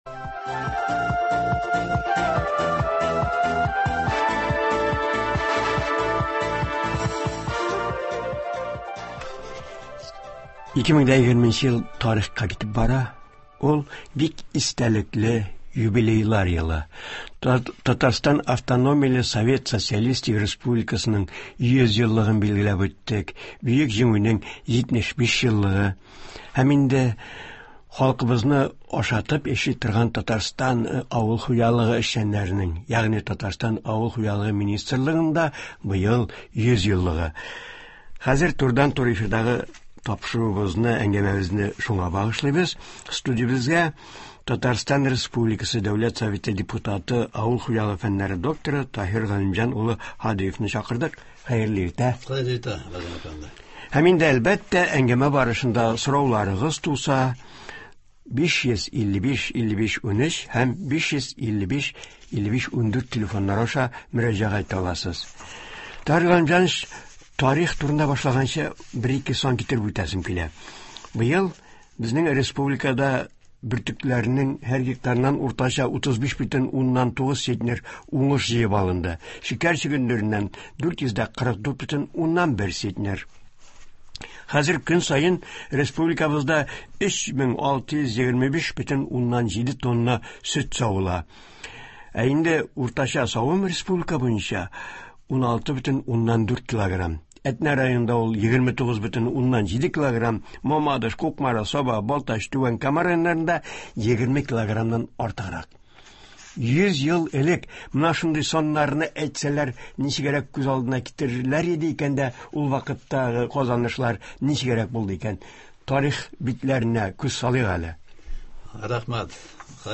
Быел ТАССРның 100 еллыгы билгеләп үтелгәндә республикабыз авыл хуҗалыгы һәм азык-төлек министрлыгының да бер гасырлык юбилее үткәрелде. Тарих, авыл эшчәннәренең быелгы казанышлары, хезмәт алдынгылары турында турыдан-туры эфирда Татарстан республикасы Дәүләт Советының экология, табигатьтән файдалану, агросәнәгать һәм азык-төлек сәясәте комитеты рәисе урынбасары, авыл хуҗалыгы фәннәре докторы Таһир Һадиев сөйләячәк, тыңлаучылар сорауларына җавап бирәчәк.